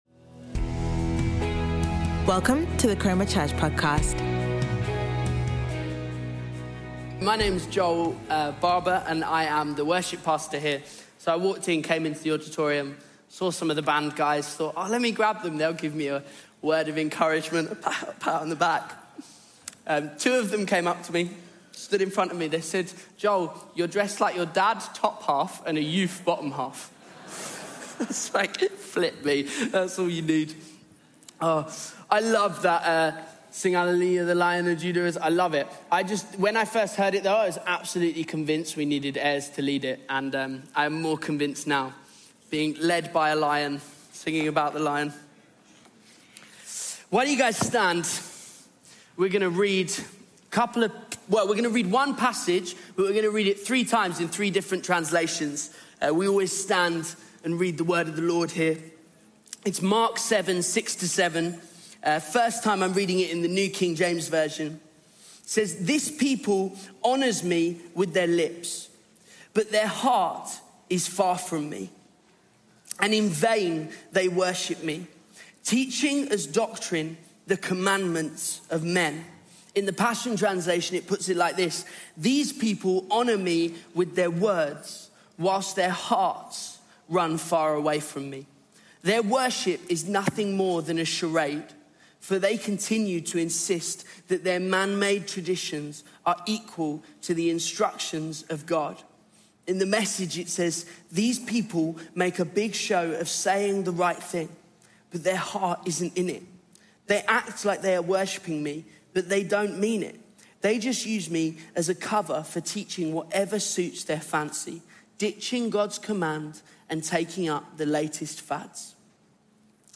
Chroma Church - Sunday Sermon The Heart of Worship